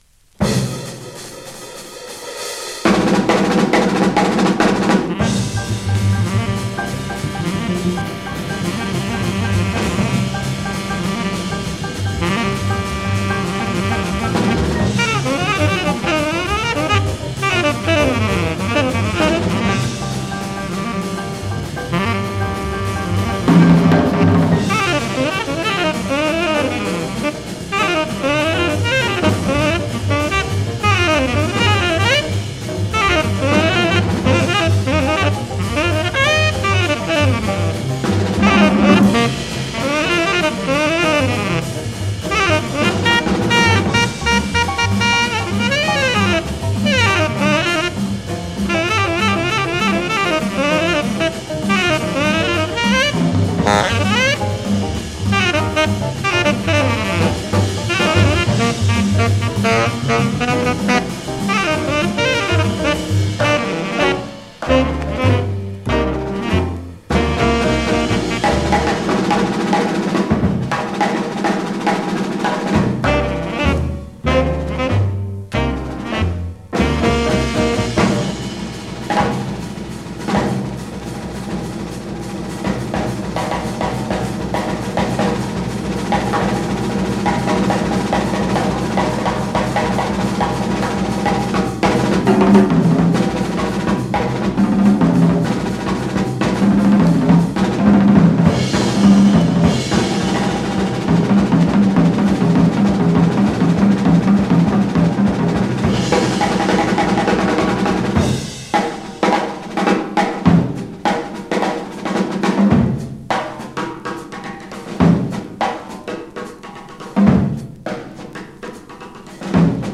日本のモダンジャズ黎明期の貴重な記録です。
NotesMono